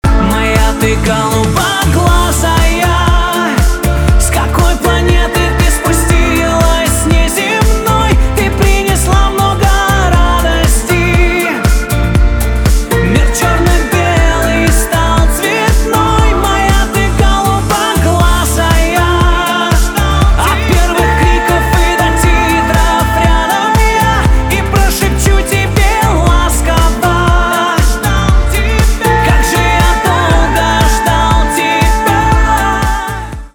поп
чувственные , красивые , гитара , битовые , милые